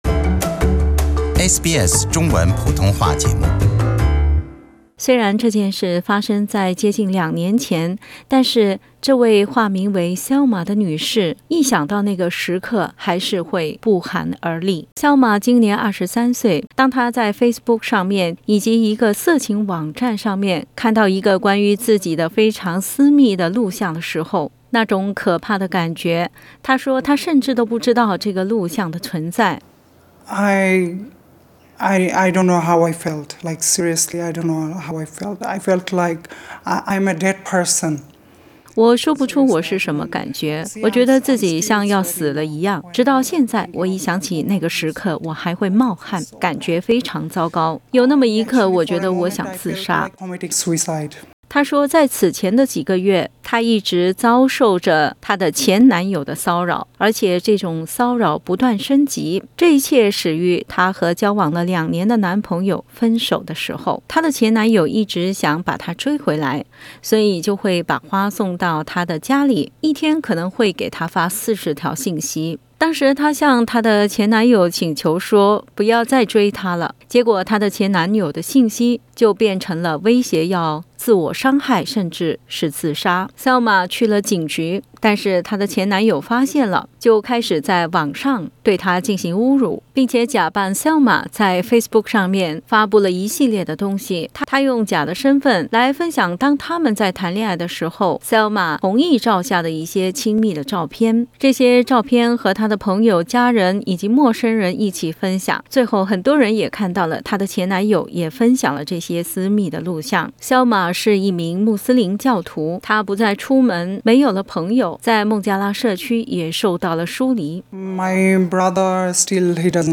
SBS采访了一位私密录像被恼羞成怒的前男友放上脸书和色情网站的女士。